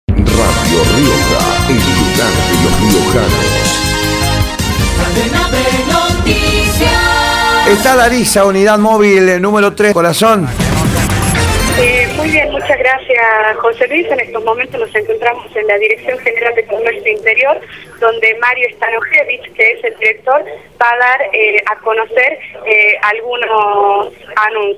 Mario Stanojevich, director Comercio Interior, por Radio Rioja
mario-stanojevich-director-comercio-interior-por-radio-rioja.mp3